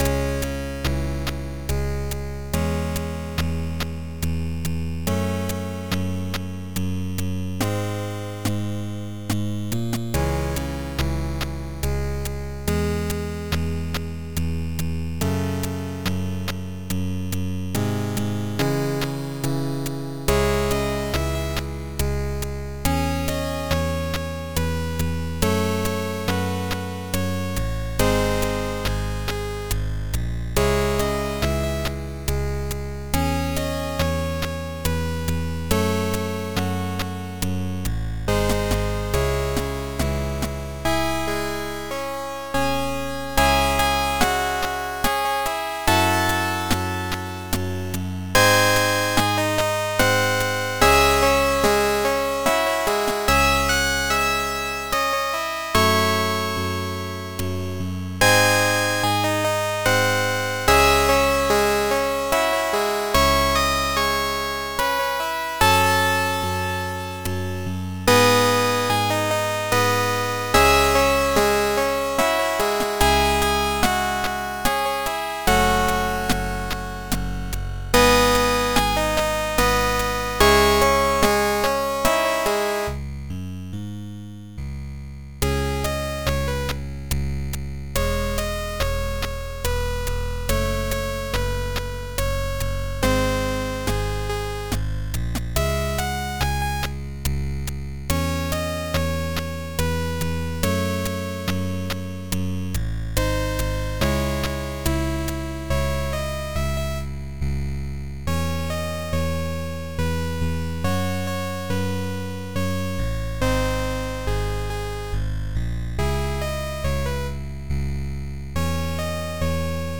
After the double chorus, we are going to add a single measure with only bass.